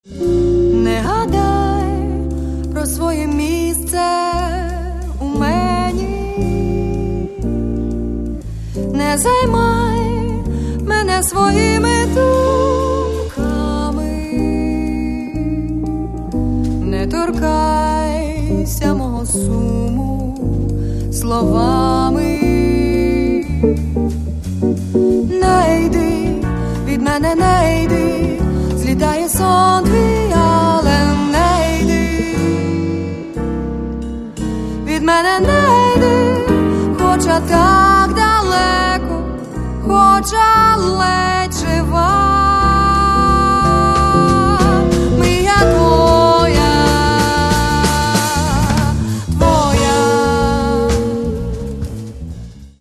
Каталог -> Джаз и около -> Джаз-вокал
vocal, back vocal
keyboards
soprano sax, alto sax
guitar
bass guitar
drums